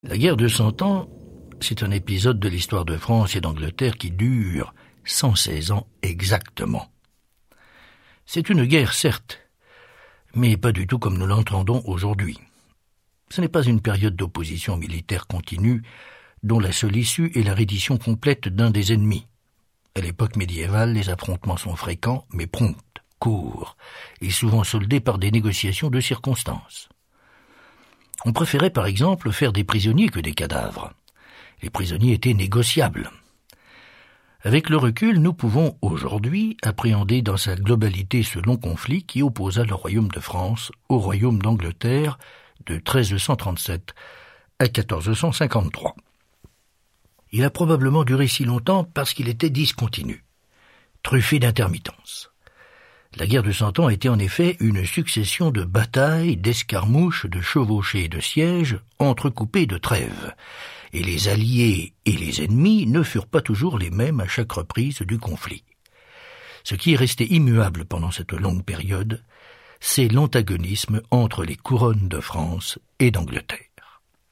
Narrateur : Bernard-Pierre Donnadieu